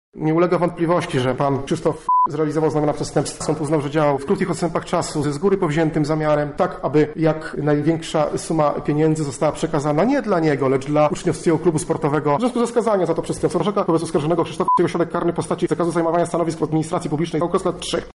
– mówi sędzia Piotr Chwedeńczuk.